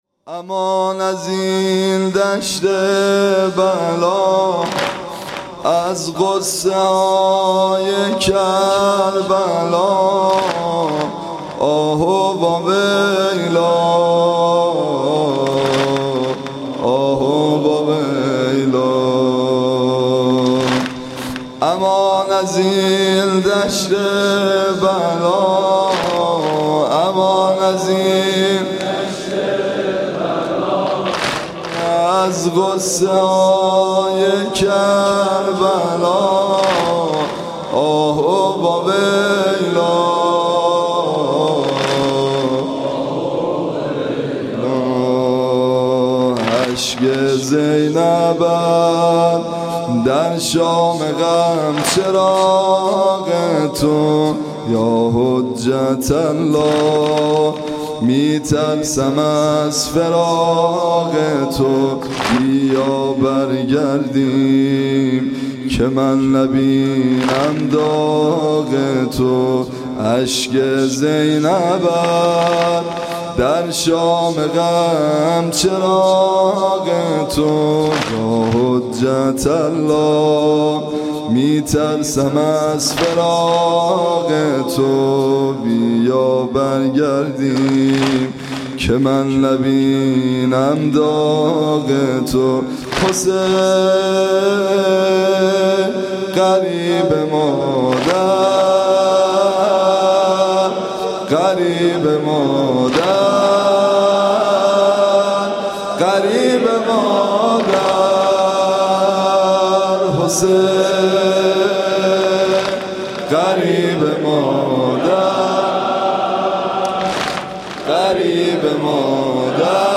صوت مراسم شب دوم محرم ۱۴۳۷ هیئت ابن الرضا(ع) ذیلاً می‌آید: